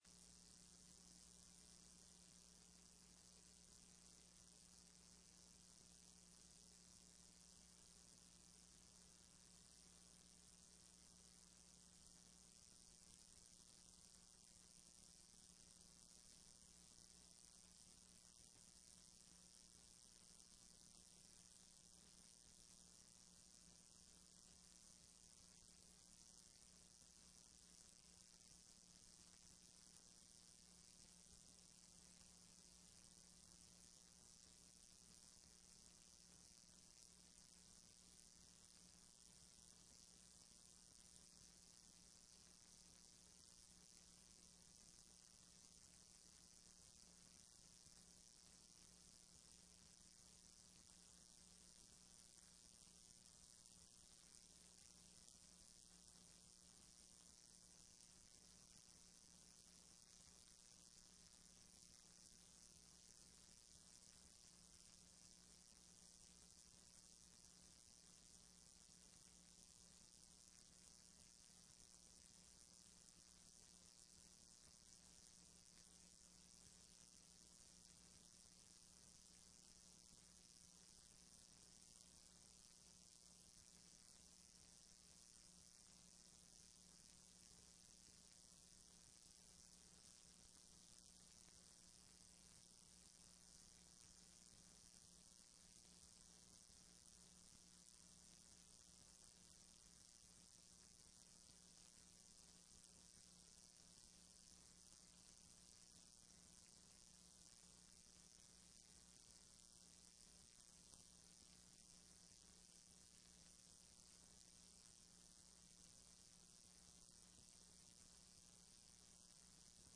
TRE-ES - Sessão 15.07.15